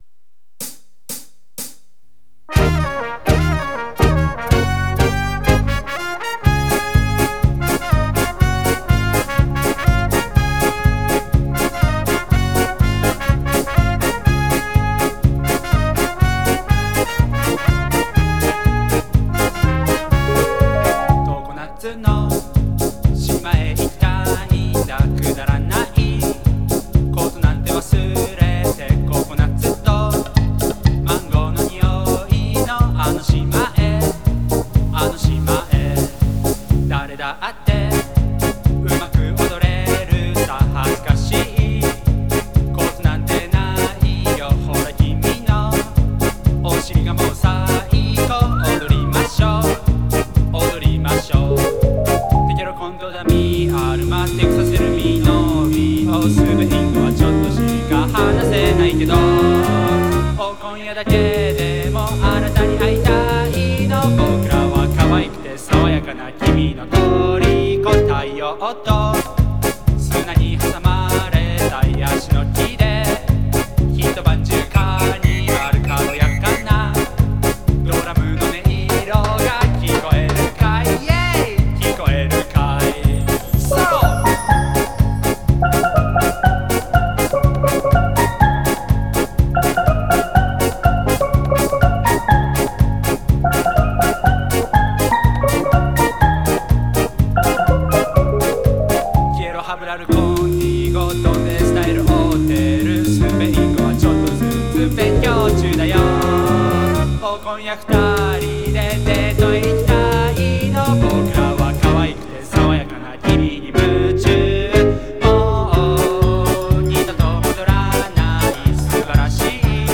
テクノ+カリプソのような曲です。